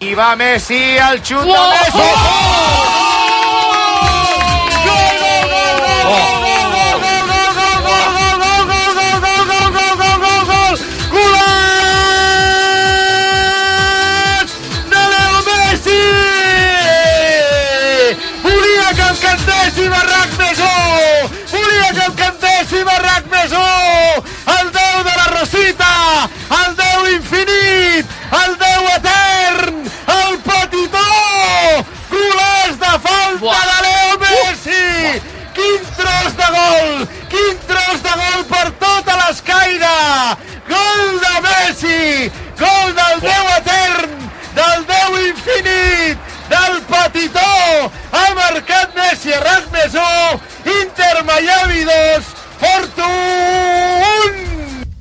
Cant del gol de Leo Messi, de l'Inter de Miami, a la Copa del Món de Clubs, al partit de futbol masculí enfront del Porto
Esportiu